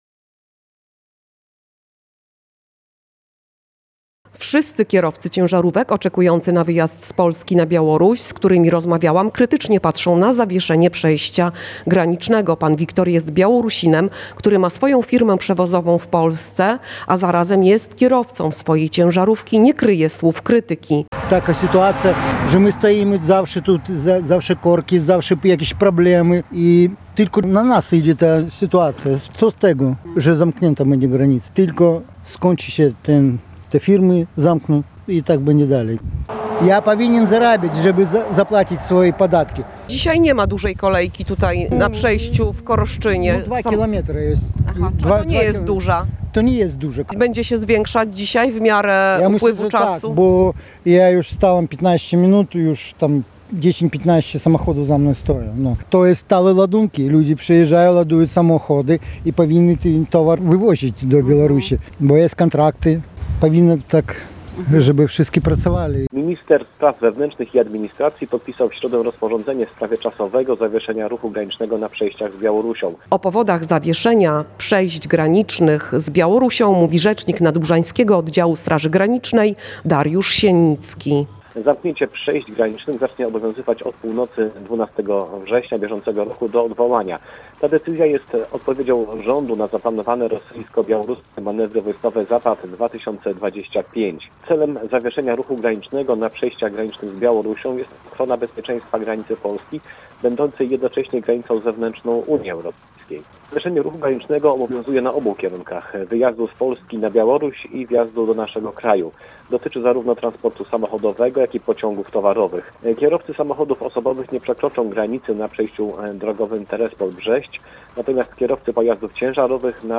Radio Lublin sprawdziło, jak przed wprowadzanymi zmianami wygląda sytuacja na przejściach granicznym: dla ciężarówek w Koroszczynie i samochodów osobowych w Terespolu.